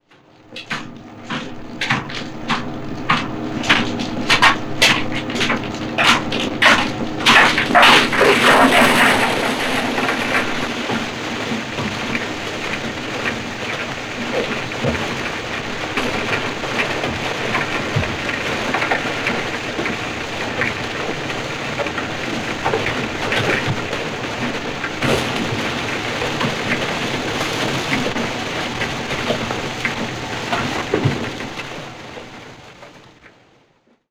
• coal falling down a shute in a coal mine.wav
Coal_falling_down_a_shute_in_a_coal_mine_jvO.wav